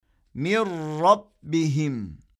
Bu durumda ğunne yapılmaz, ses tutulmaz.
Türkçede “On Lira” için “OLLira” okunması gibidir.